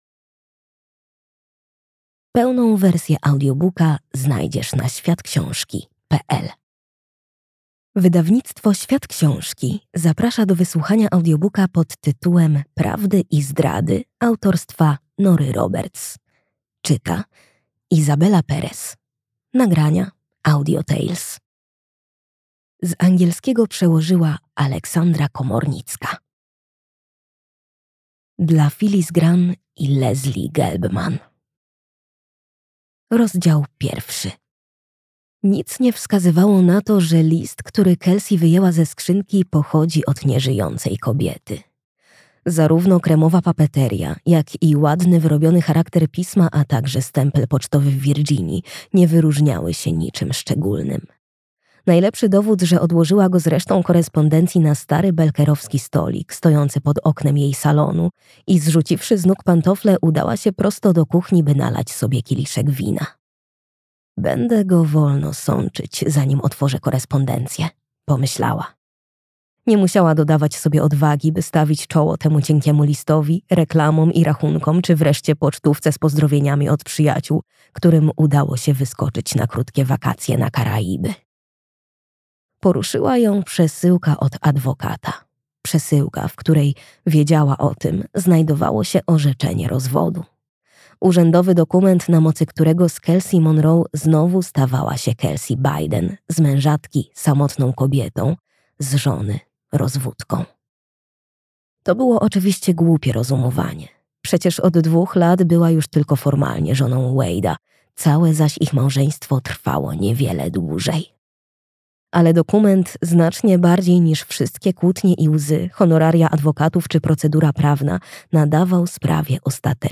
Audiobook + książka Prawdy i zdrady, Nora Roberts.